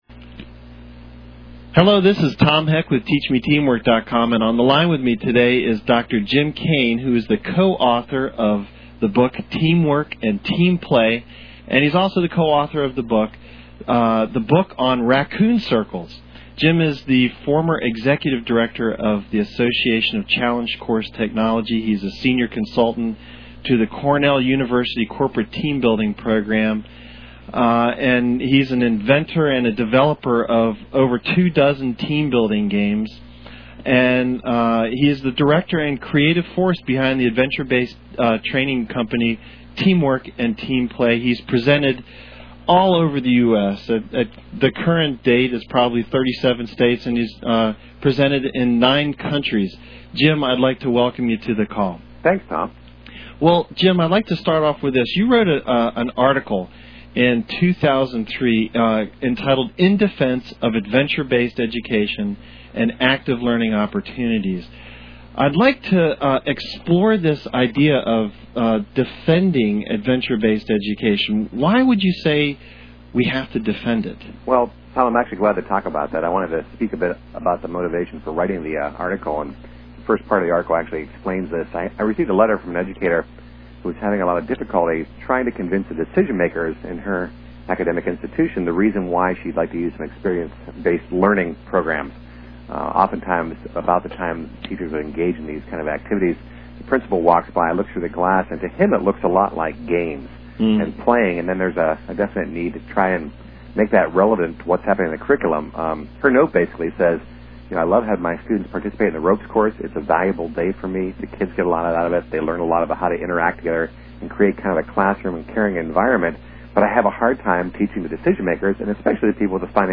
Audio Interview